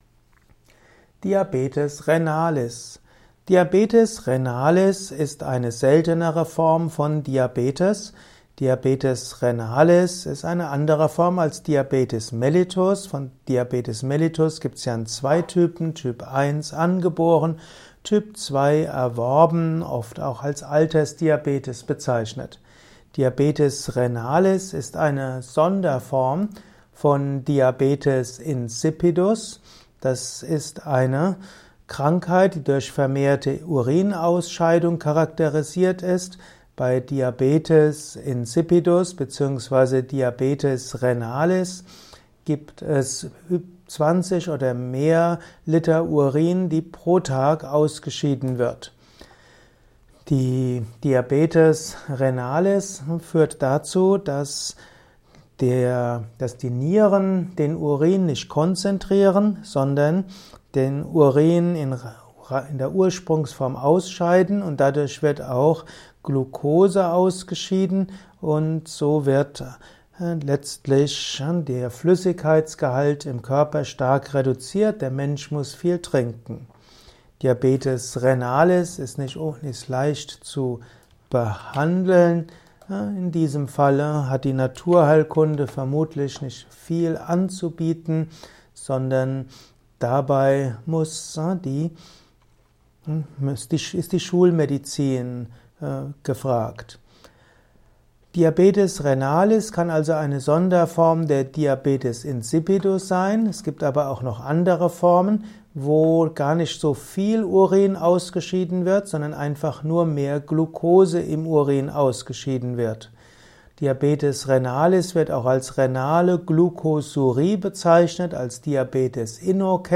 Ein Kurzvortrag über den Begriff Diabetis Renalis